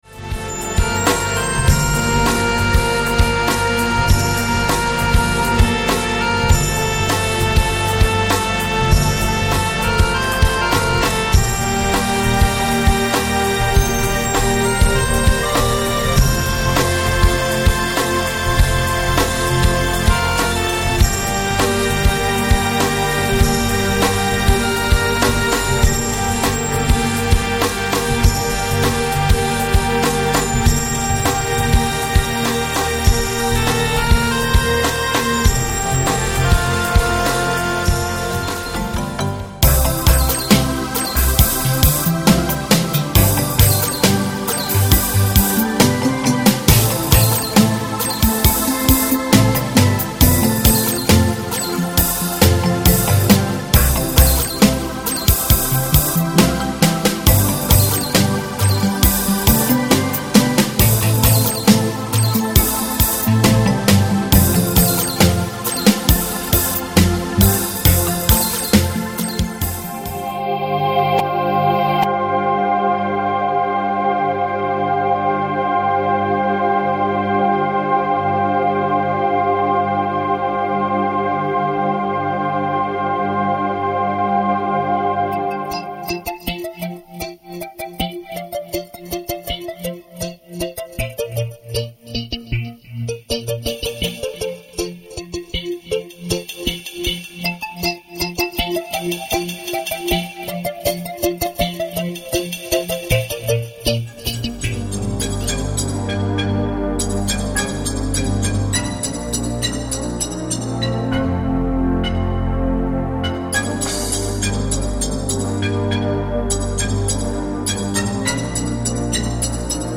Mit Anspielern aller Titel
Sanftes Pop Thema - Charakter: nachdenklich
Sonniges Marimba Thema im Reggae Style
Weite Klangflächen mit sphärischen Walgesängen
Melancholisches Piano Thema mit Pizzicato Strings
Romantisches Piano Thema mit Streichern und Holzbläsern
Dark Wave Thema mit E-Gitarre und einer sanften Bassfigur
Akkustische Gitarre, Streicher, Harmonika - Ein Latin Thema